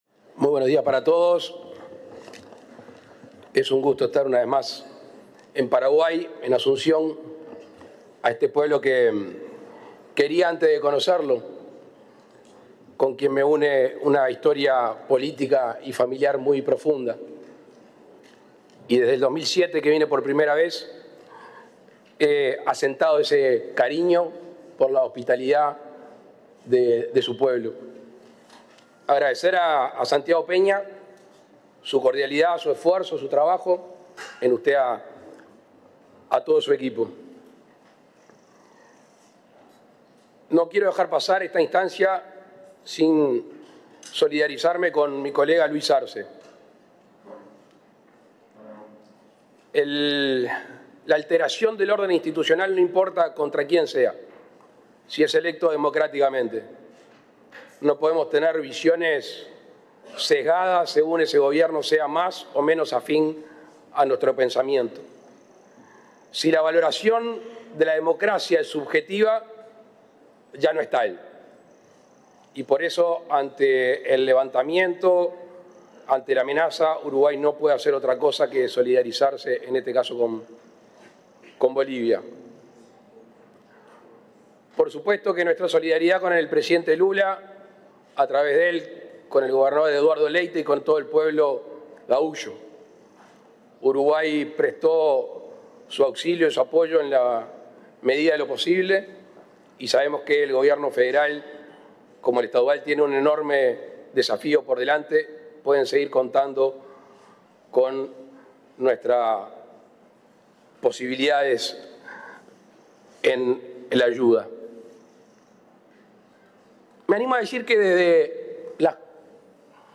Palabras del presidente Luis Lacalle Pou
El presidente de la República, Luis Lacalle Pou, participó, este lunes 8 en Asunción, Paraguay, en la Cumbre de Jefes de Estado del Mercosur.